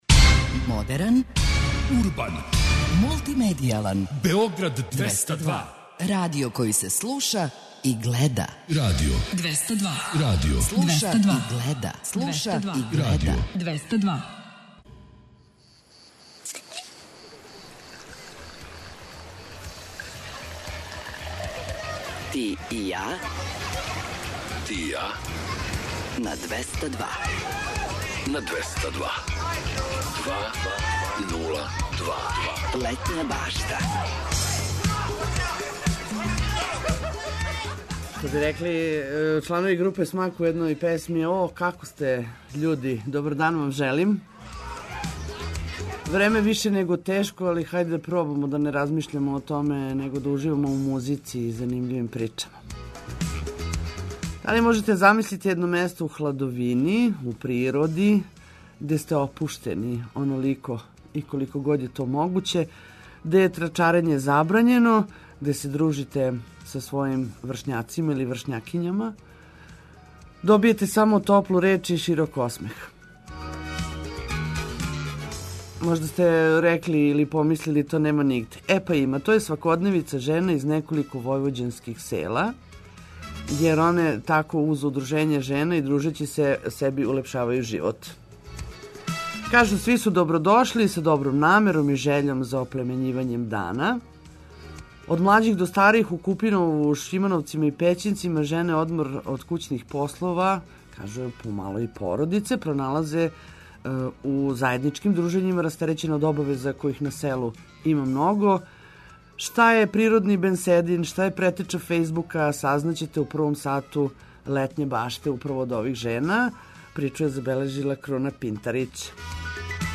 Зачин пријатном дану биће ведра музика, лепе вести, водич кроз текуће манифестације у Србији, а на неке вас и шаљемо!